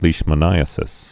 (lēshmə-nīə-sĭs)